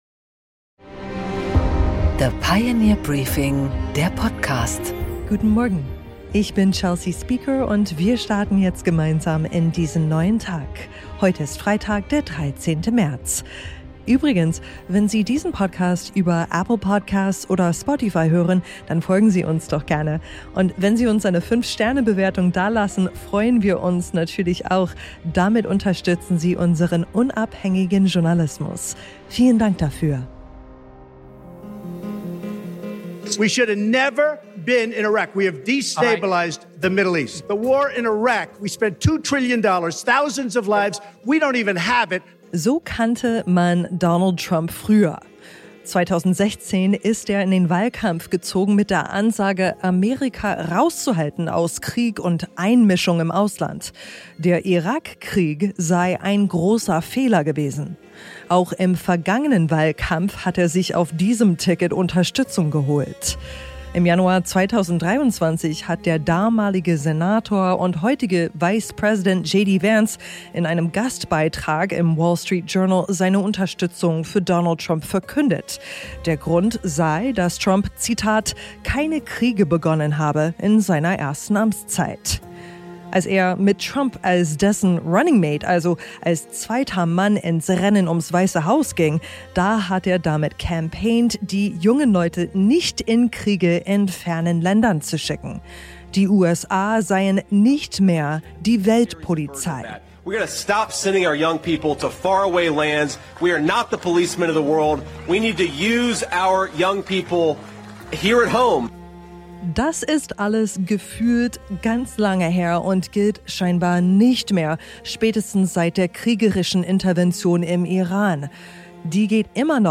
Interview mit Prof. Clemens Fuest